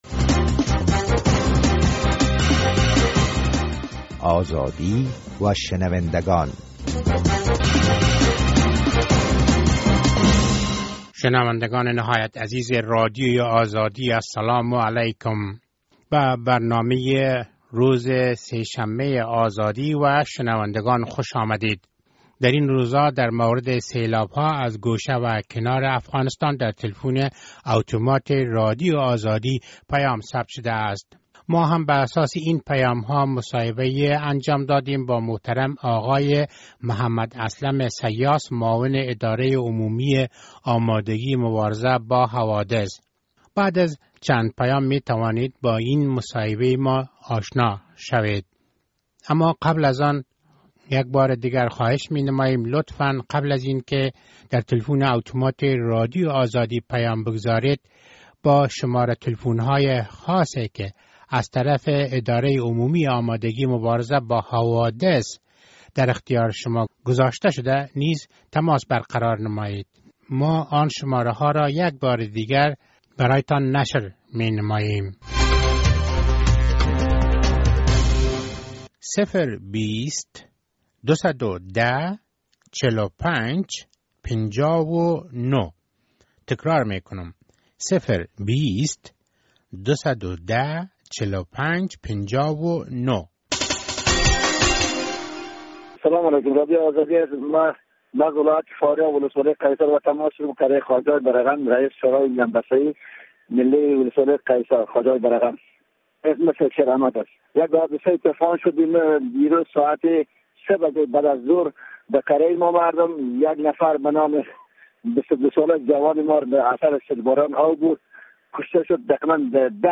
پیام ها در مورد سیلاب های اخیر در ولایات مختلف افغانستان و در رابطه مصاحبه با معاون اداره مبارزه با حوادث آقای محمد اسلم سیاس...